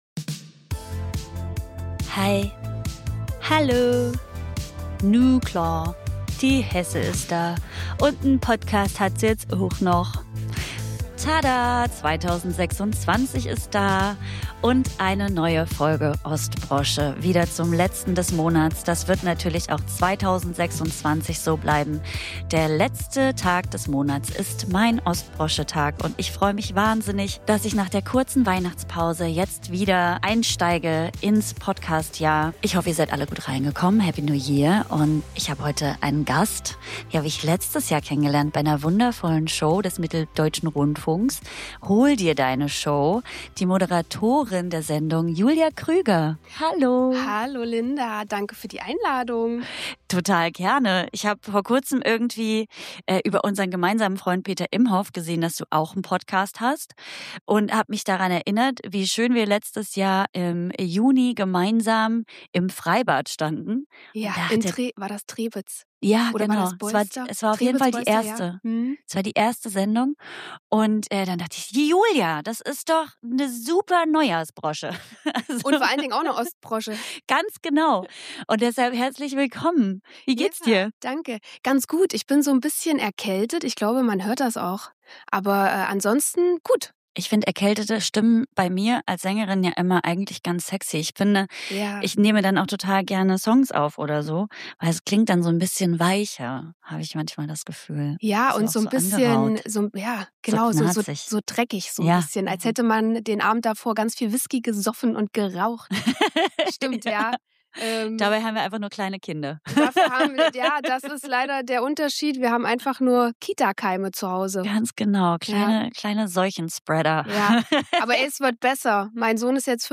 Ein Gespräch, das nicht laut sein will, sondern bleiben.